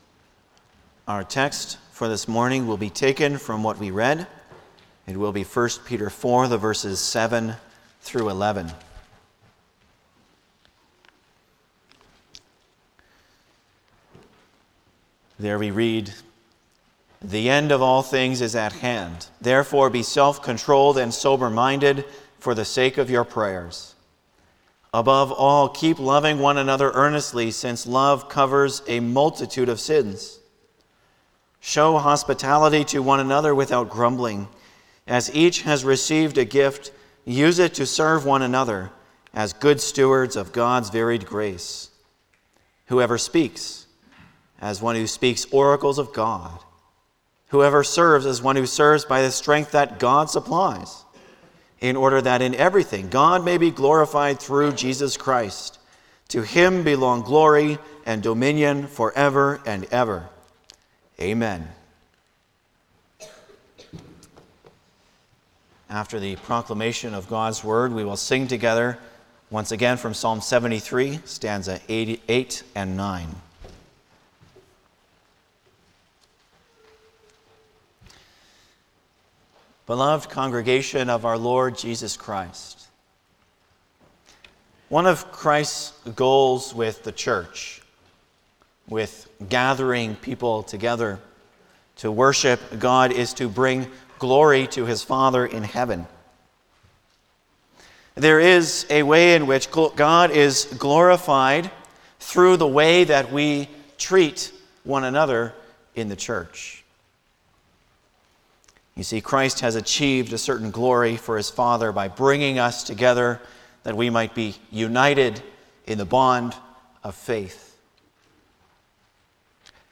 Service Type: Sunday morning
07-Sermon.mp3